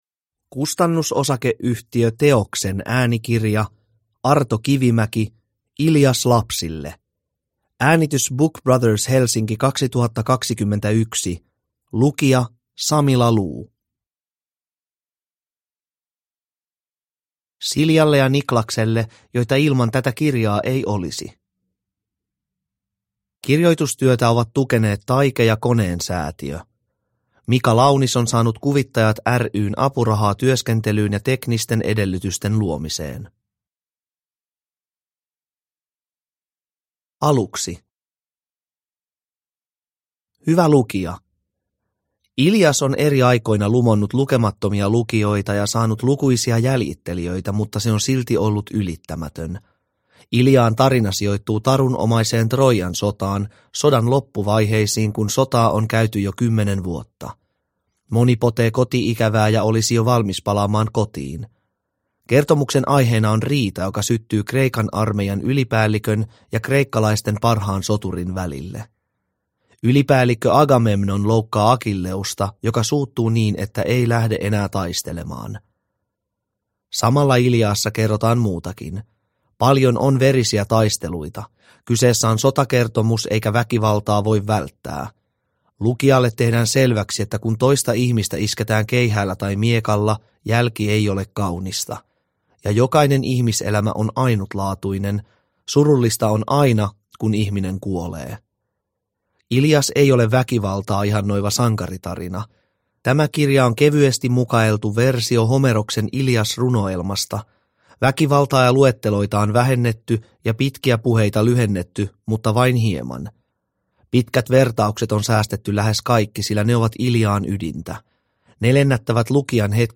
Ilias lapsille – Ljudbok – Laddas ner
Uppläsare